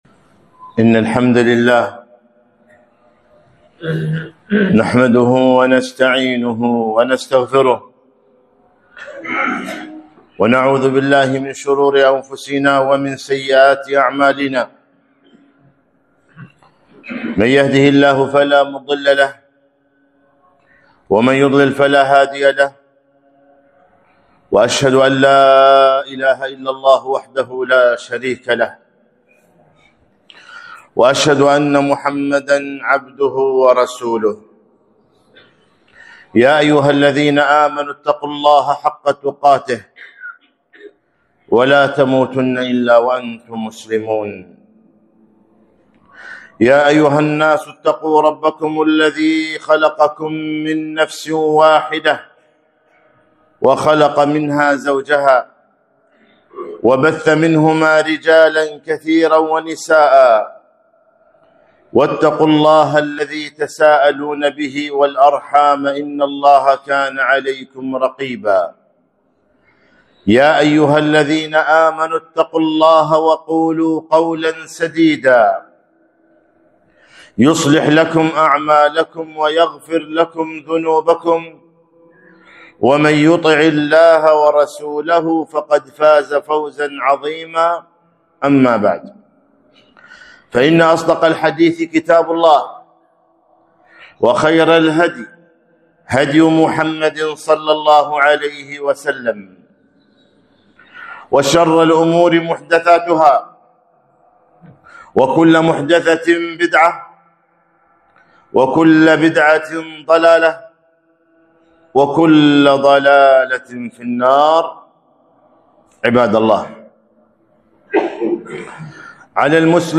خطبة - أثر الذنوب والمعاصي